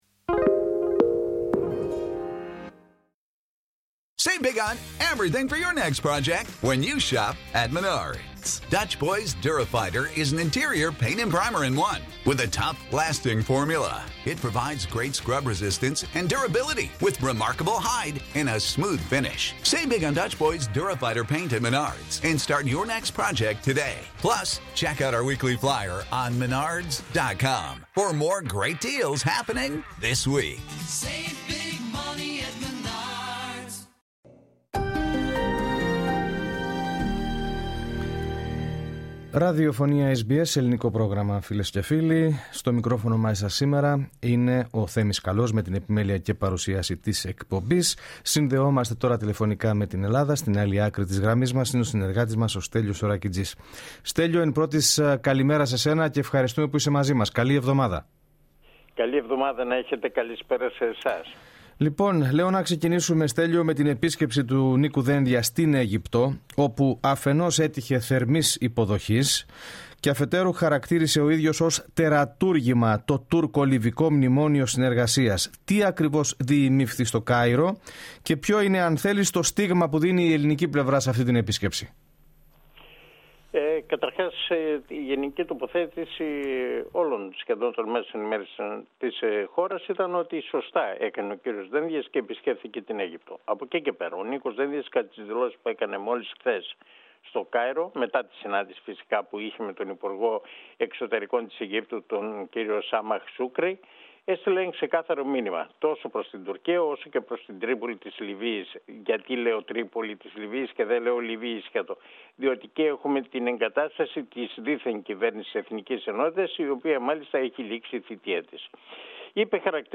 Για να ακούσετε περισσότερες ειδήσεις από την ανταπόκρισή μας από Ελλάδα, πατήστε το play κάτω από τον τίτλο της είδησης.